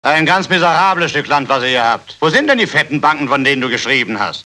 Sound file of German dubbing actor,